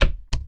New sound added for toggle to make it different from parameter change click.
minertools_toggle.ogg